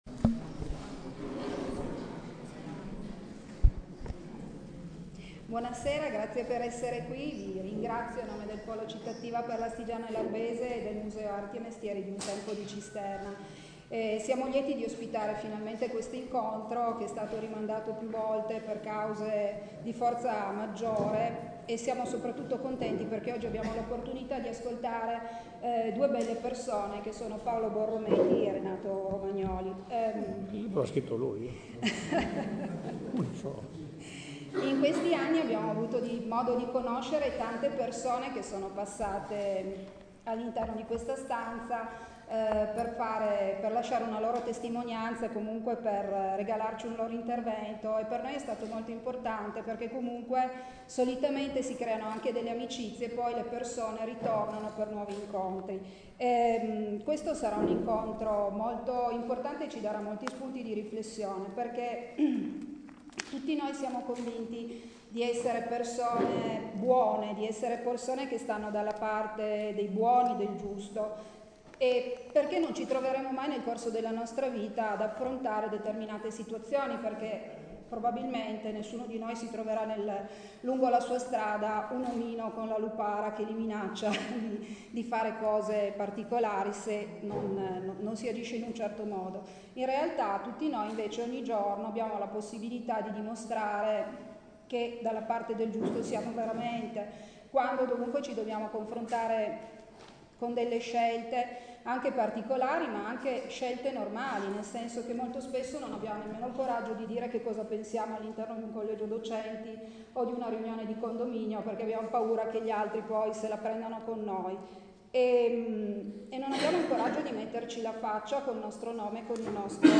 IL GIORNALISTA PAOLO BORROMETI AL CASTELLO DI CISTERNA D’ASTI